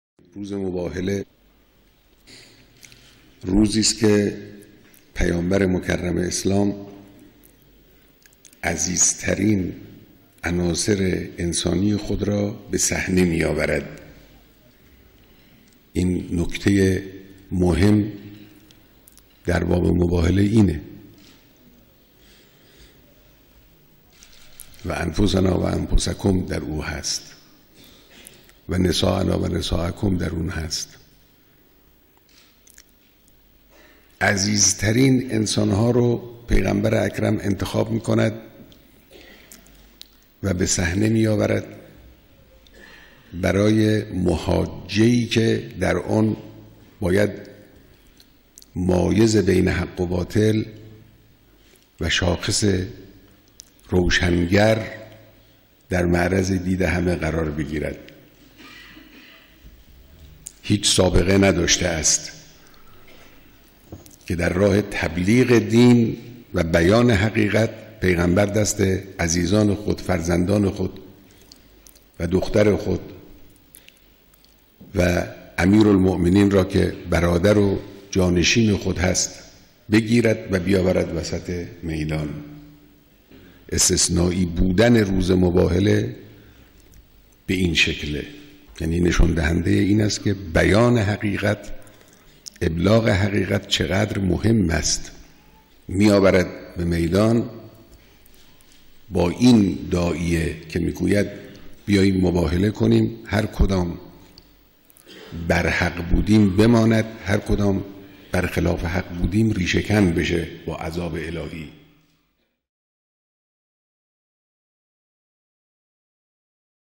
صوت | بیانات مقام معظم رهبری در مورد مباهله